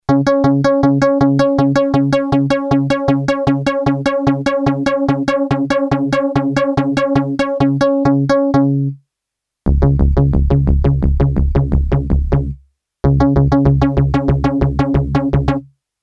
XP: DUAL mode detune range